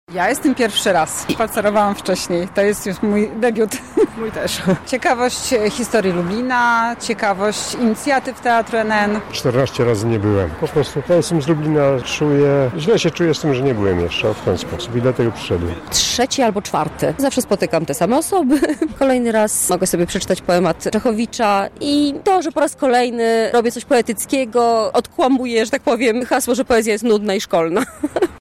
Wszystkim miłośnikom poezji Czechowicza towarzyszyła nasza reporterka